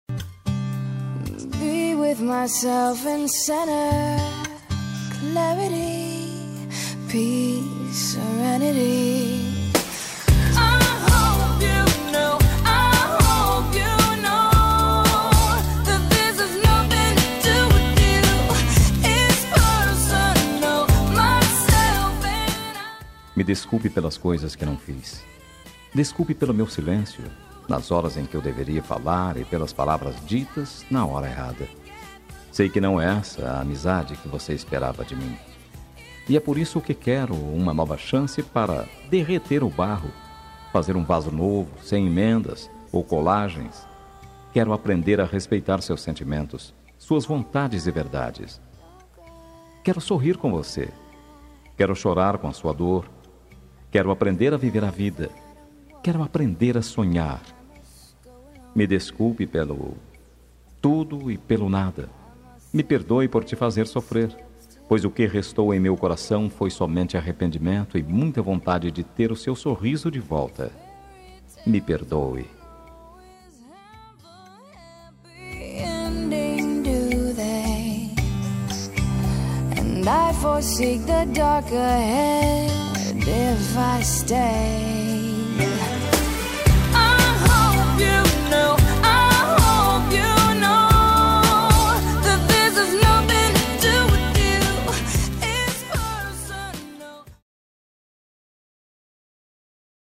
Telemensagem Amizade – Voz Masculina – Cód: 01321 – Desculpas